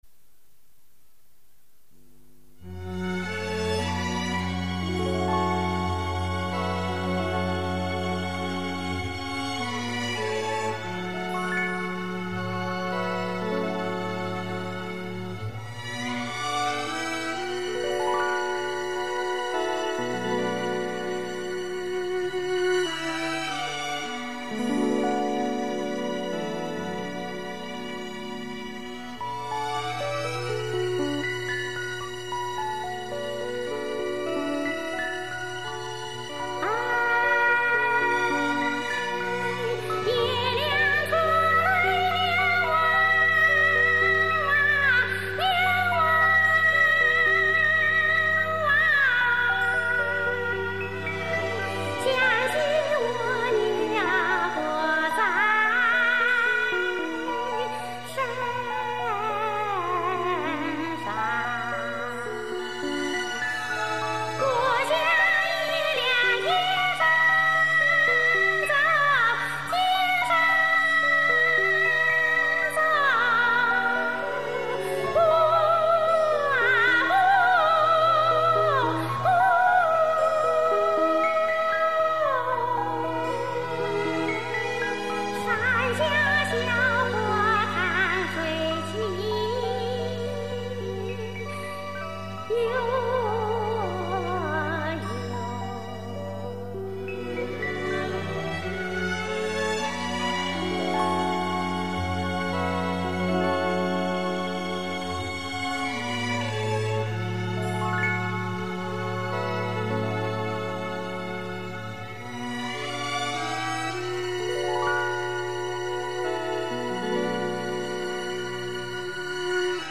收则婉约秀丽，放则高亢嘹亮，静则柔美清涵，动则爽利畅达。
云南滇西民歌